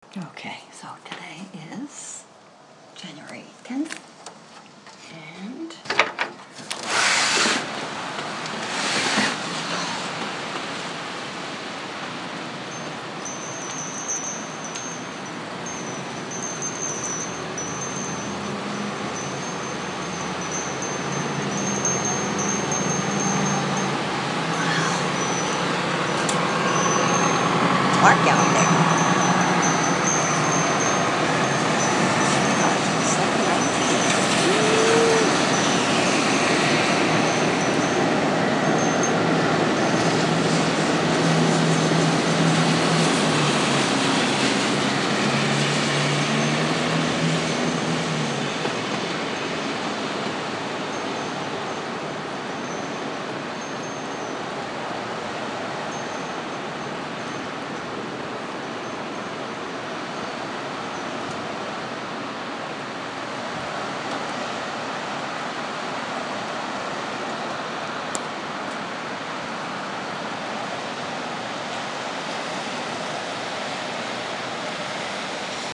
Brooklyn.